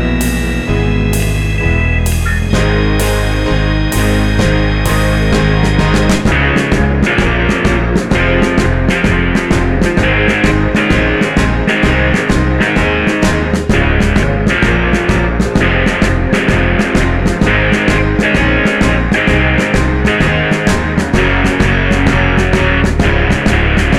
Five Semitones Down Glam Rock 3:44 Buy £1.50